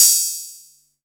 VEC3 Ride
VEC3 Cymbals Ride 14.wav